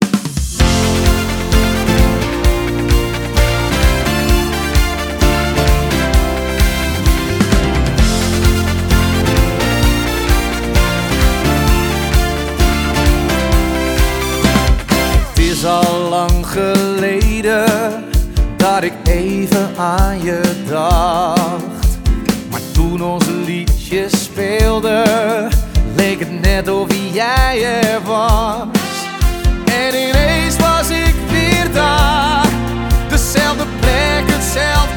Акустические струны и тёплый вокал
Жанр: Фолк